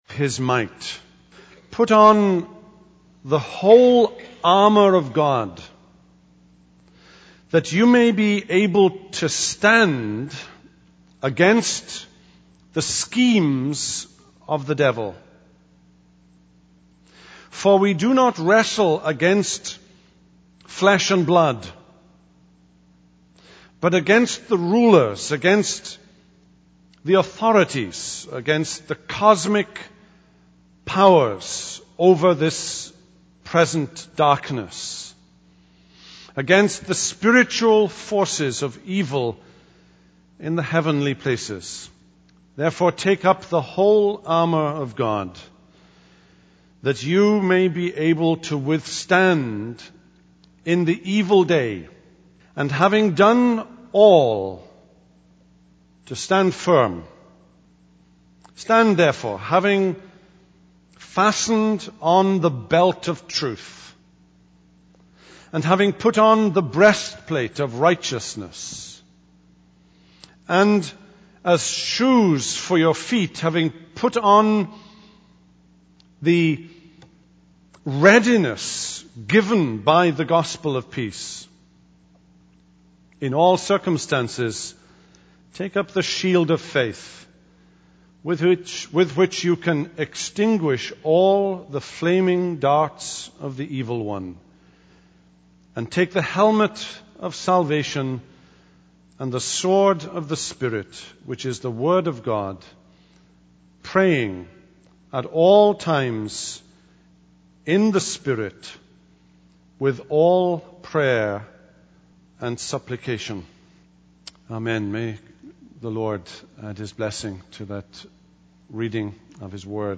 Wednesday Evening November 20, 2007